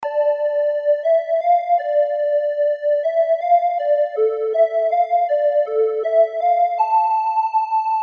Tag: 120 bpm Hip Hop Loops Flute Loops 1.35 MB wav Key : Unknown